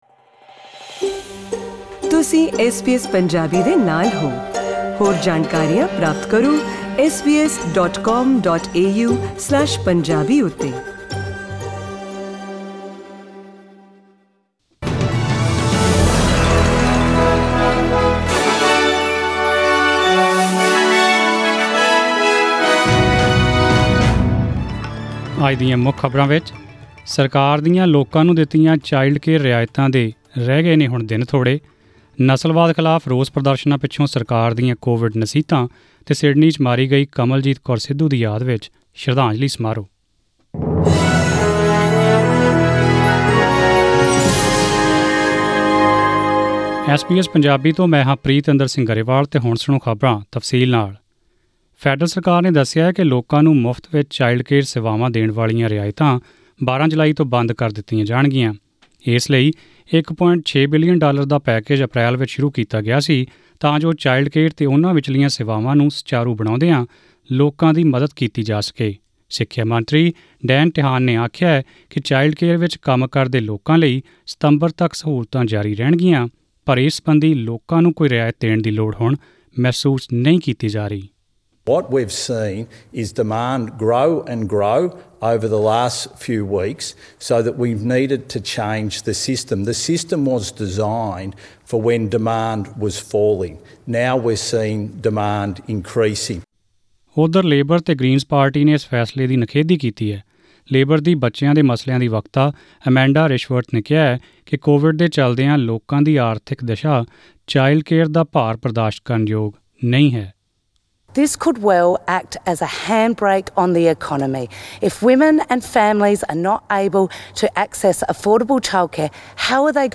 Click on the player above to listen to the news bulletin in Punjabi.